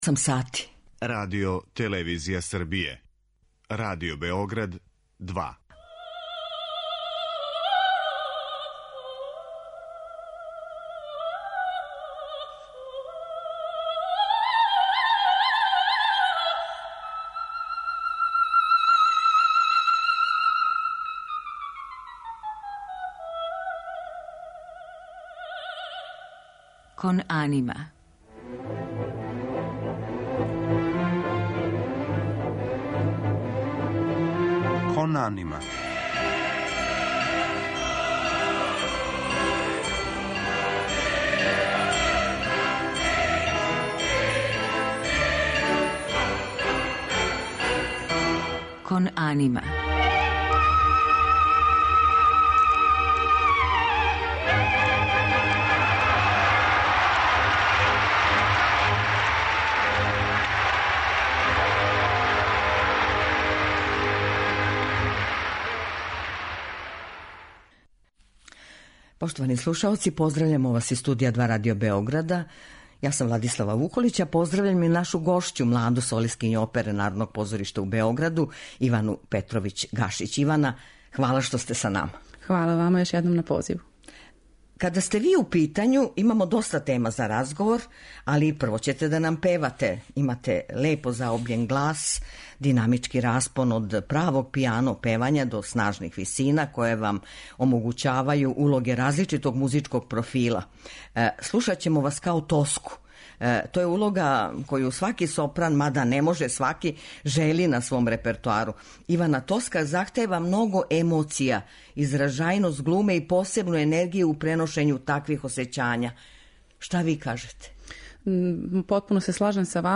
Одликују је леп, заобљен глас, чисте висине, дубоко доживљене емоције и избалансирана динамика.